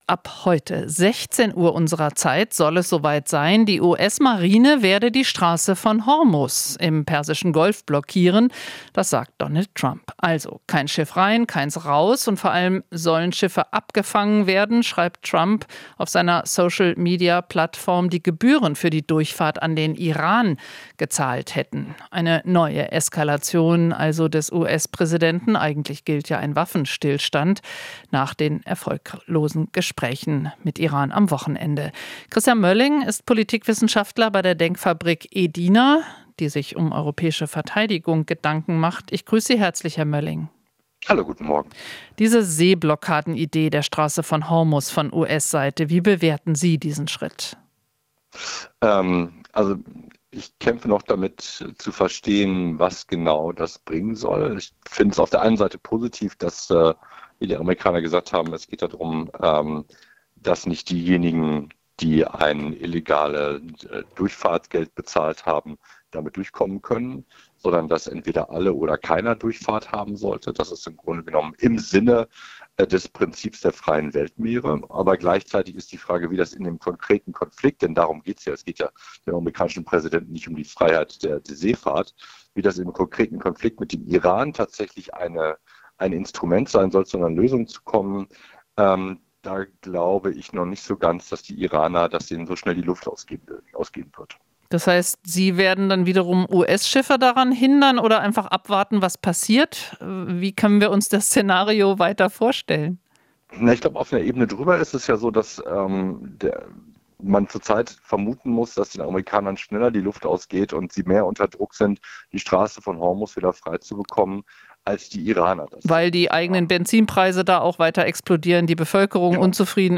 In Interviews, Beiträgen und Reportagen bilden wir ab, was in der Welt passiert, fragen nach den Hintergründen und suchen nach dem Warum.